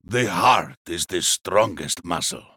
BRAUM CHAMPION SELECT VOICE: